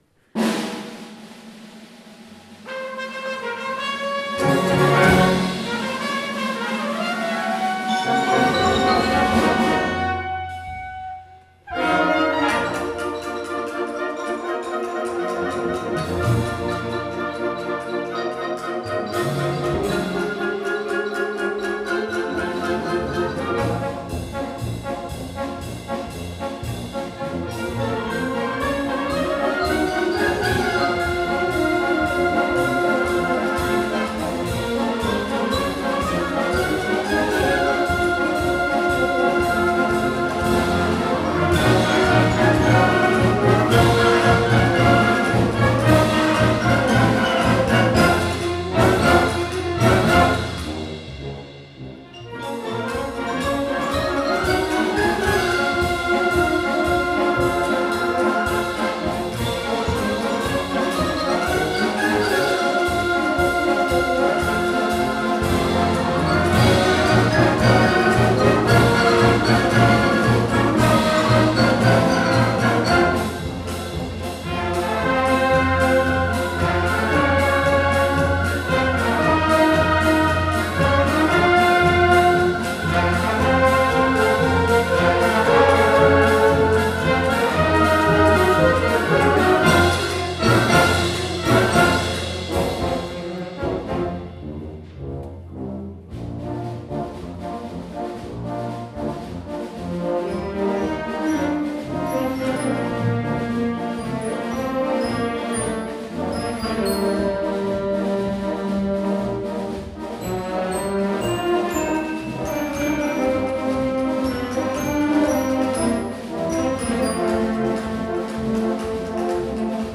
Concert de Printemps 2 avril 2023
1ère partie Orchestre d'Harmonie de Montigny-lès-Metz